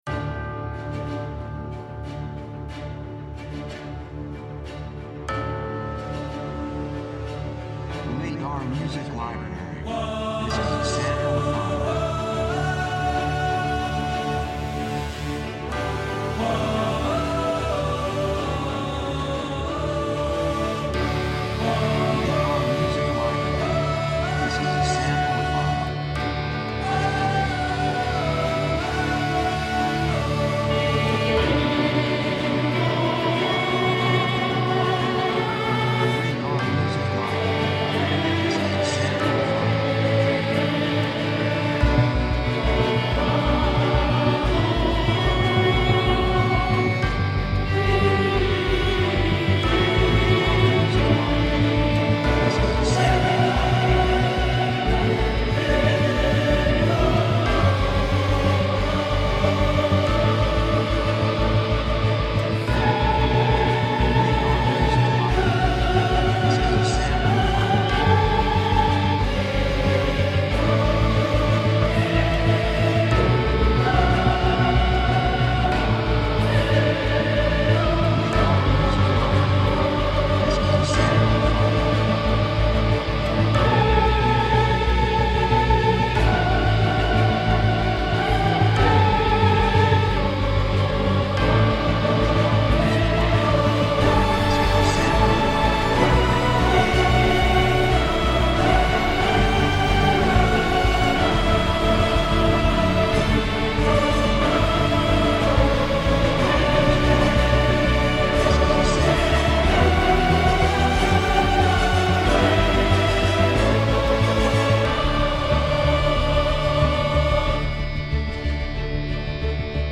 雰囲気高揚感, 決意, 喜び
曲調ポジティブ
楽器エレキギター, パーカッション, ストリングス, ボーカル, 手拍子
サブジャンルアクション, オーケストラハイブリッド
テンポミディアム